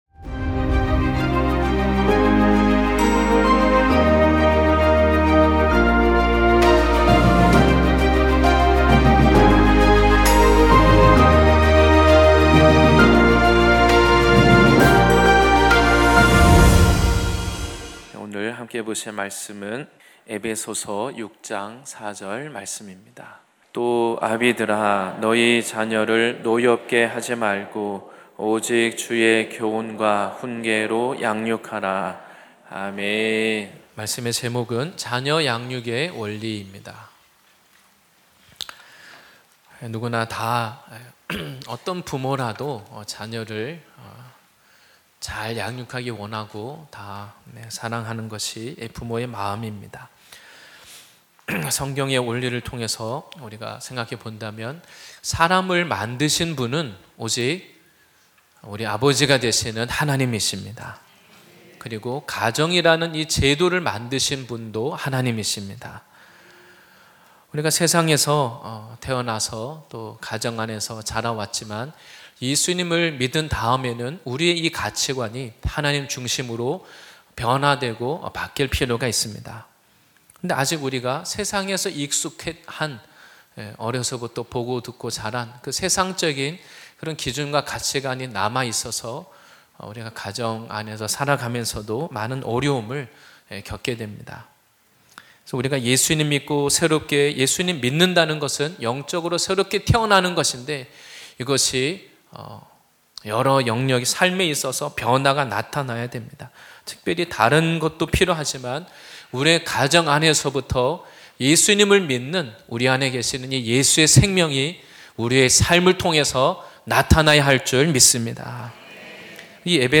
2025년6월22일 주일예배말씀